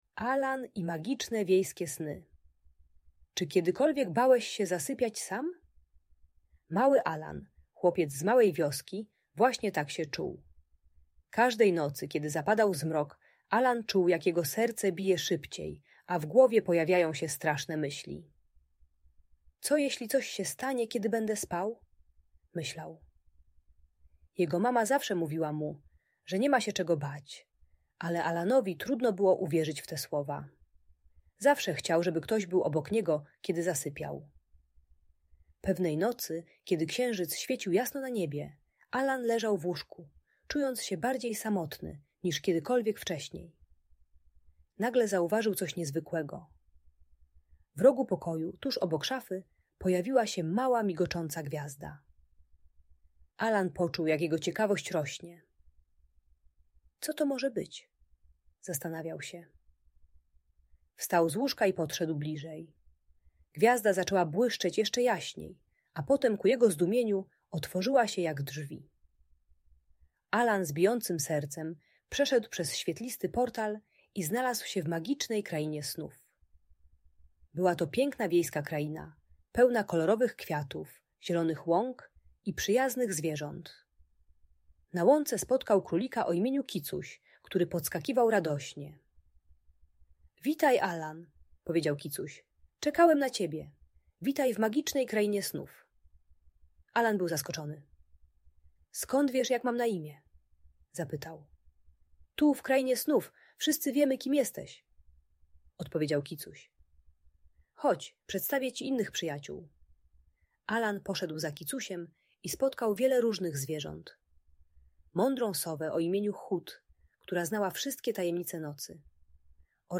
Alan i magiczne wiejskie sny - Audiobajka